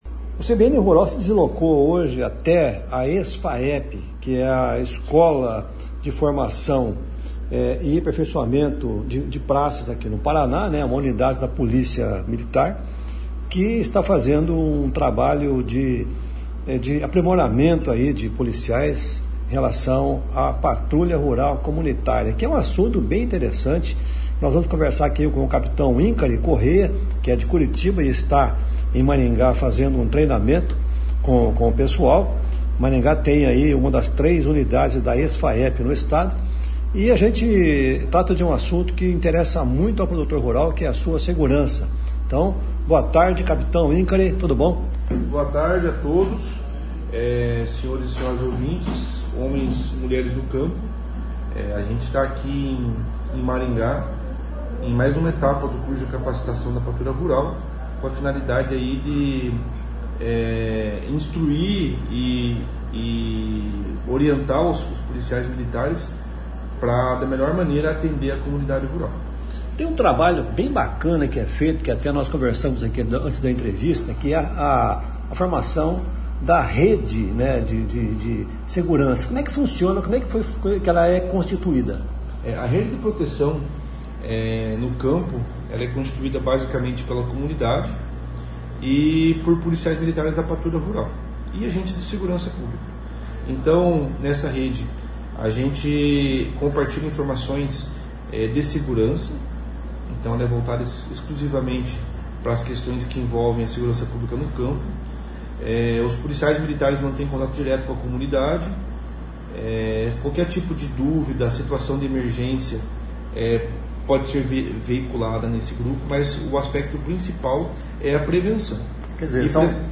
conversa